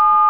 Tone7
TONE7.WAV